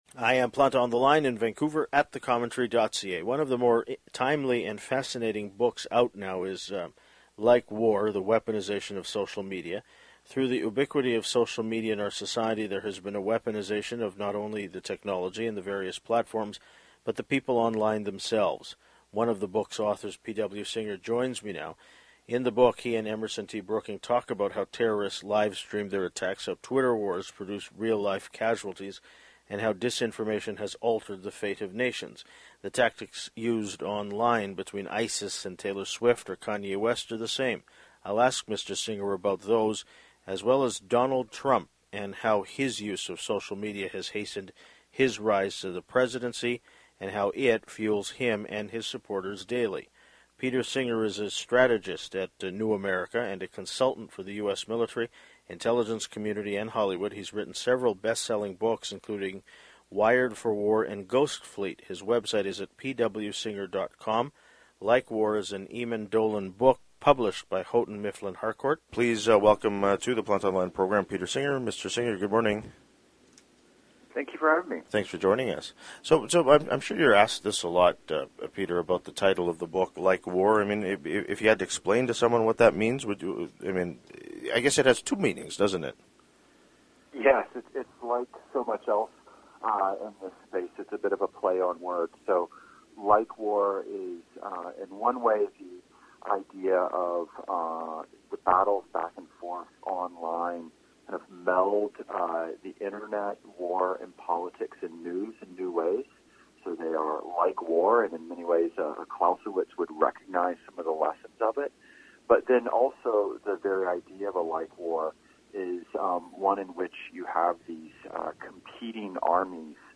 One of the book’s authors P.W. Singer joins me now. In the book, he and Emerson T. Brooking talk about how terrorists livestream their attacks, how “Twitter wars” produce real-life casualties, and how disinformation has altered the fate of nations.